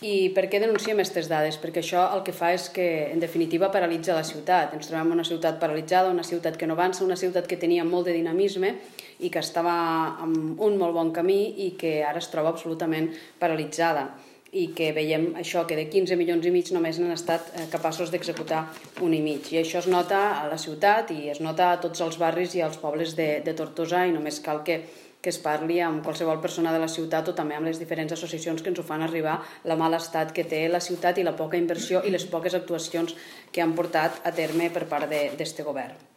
La portaveu de Junts per Tortosa, Meritxell Roigé, ha valorat molt negativament aquesta situació i ha lamentat  que la ciutat ha perdut dinamisme.…